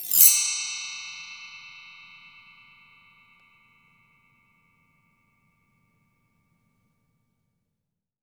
BELL TREE S2.WAV